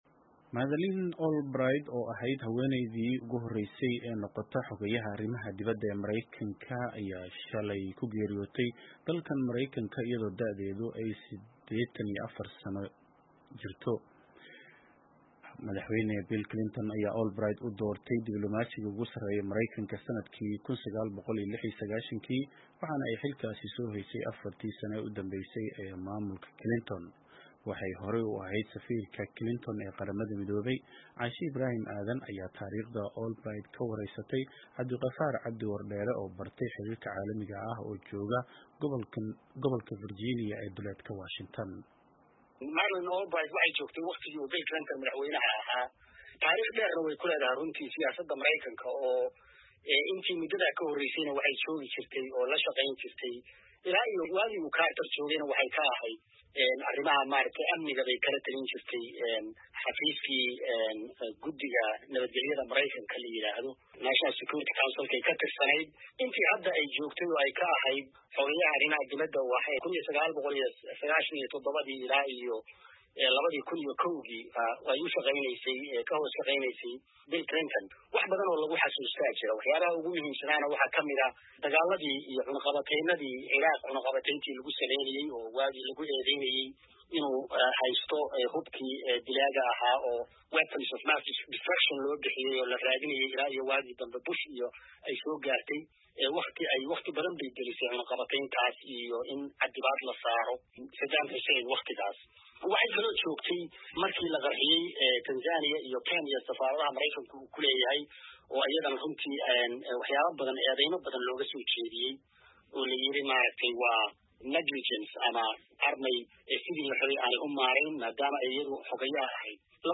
ayaa taariikhda Albright ka wareysatay